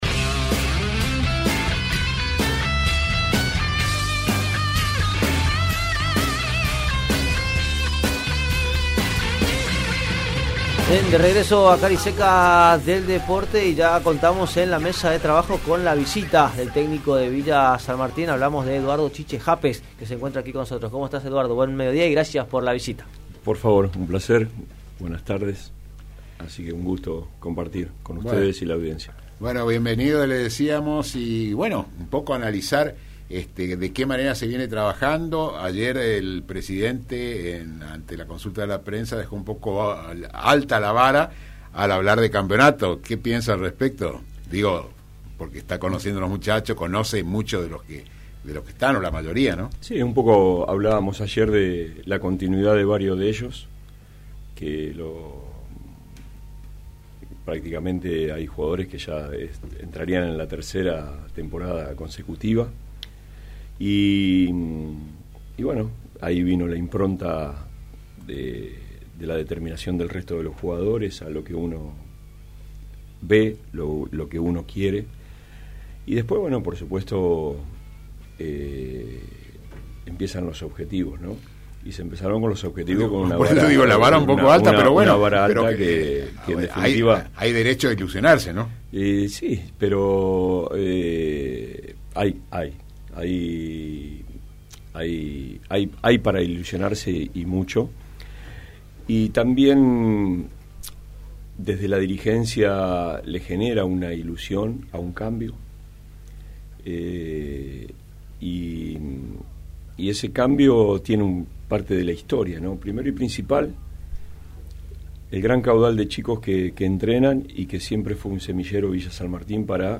El programa se emite por Radio Provincia
en una entrevista exclusiva de la 101.5 asumió el reto con un mix de pragmatismo e ilusión.